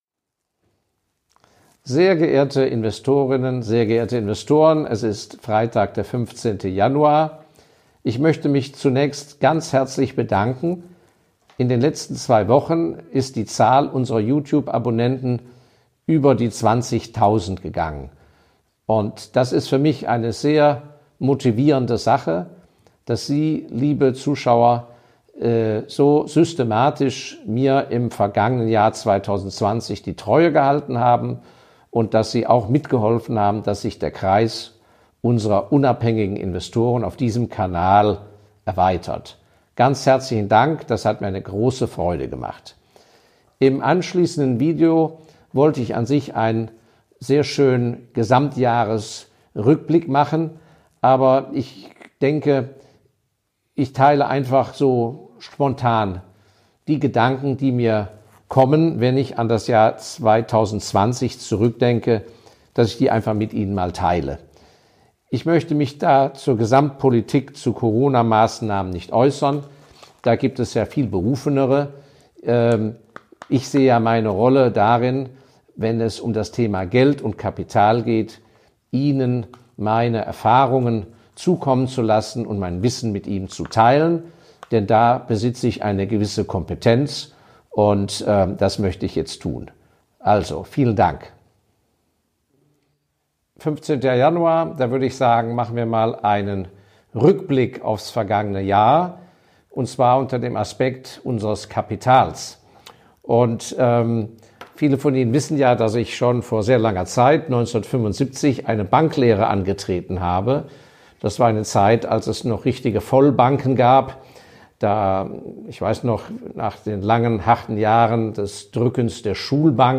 Wie ist es möglich nicht dem psychologischen Sog des Herdentriebs an den Weltbörsen zu erliegen. Warum das „Value Investing” wie es die meisten Investoren interpretieren tot ist und vieles mehr, möchte ich in diesem Podcast in Form von spannenden Interviews beantworten...